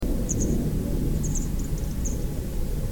Gebirgsstelze